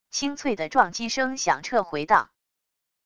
清脆的撞击声响彻回荡wav音频